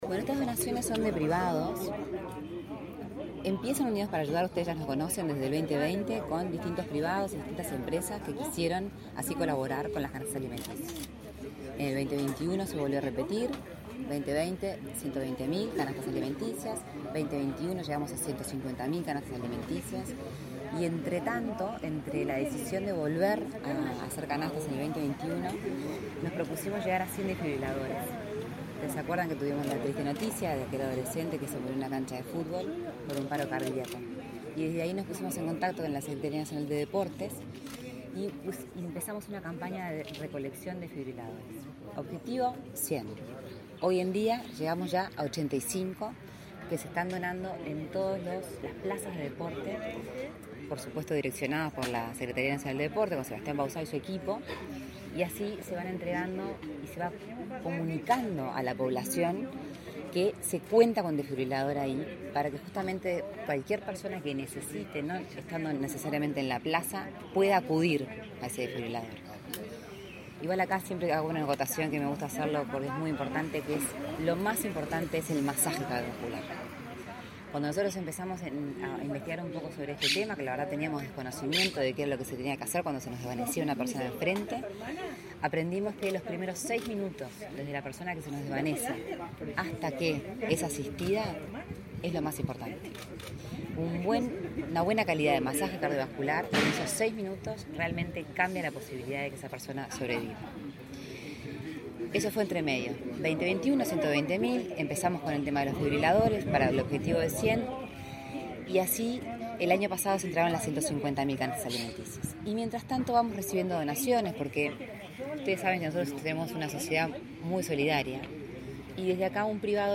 Declaraciones a la prensa de Lorena Ponce de León
Su impulsora, Lorena Ponce de León, dialogó con la prensa sobre la importancia de la donación.